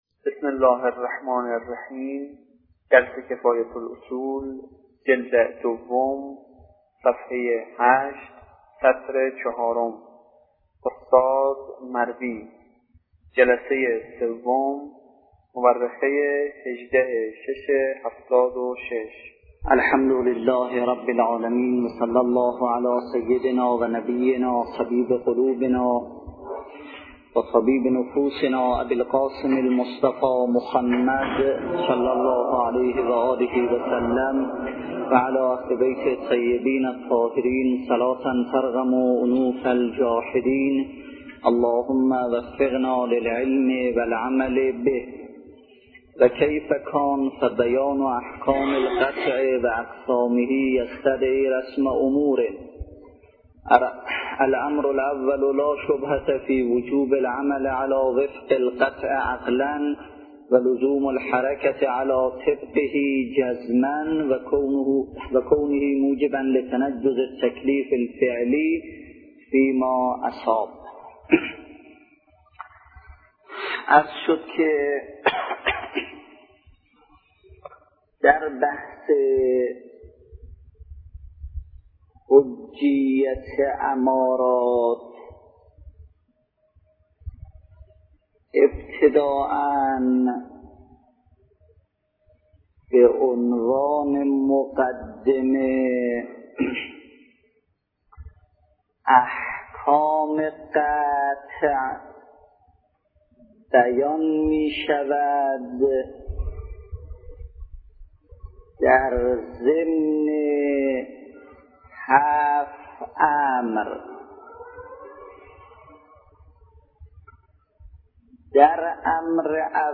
نیوایج